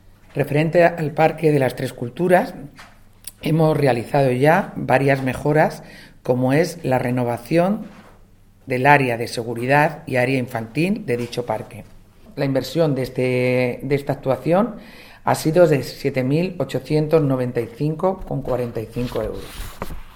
La concejala de Parques y Jardines, Marta Medina, ha informado hoy en rueda de prensa de las mejoras llevadas a cabo en el Parque de las Tres Culturas y, en concreto, en la zona infantil, donde se ha renovado el suelo de caucho con un presupuesto cercano a los 8.000 euros, exactamente de 7.895,45 euros.
AUDIO. Marta Medina, concejala de Parques y Jardines